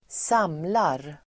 Uttal: [²s'am:lar]